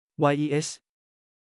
mp3-output-ttsfree(dot)com (4).mp3